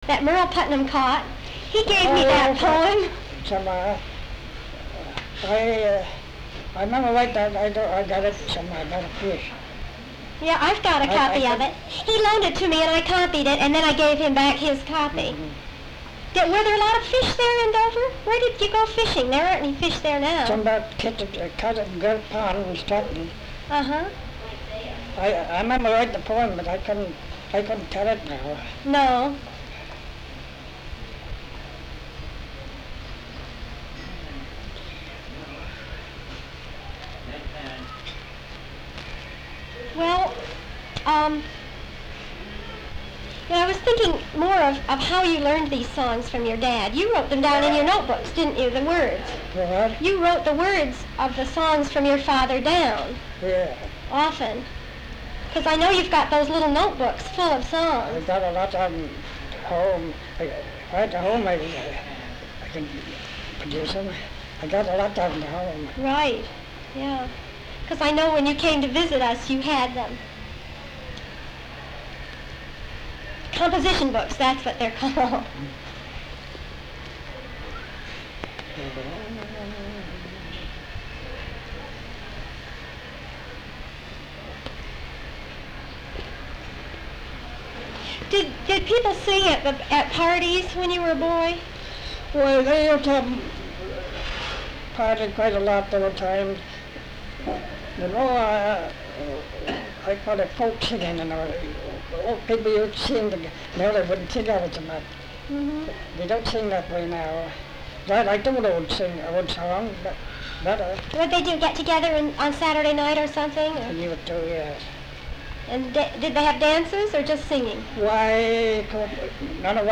sound tape reel (analog)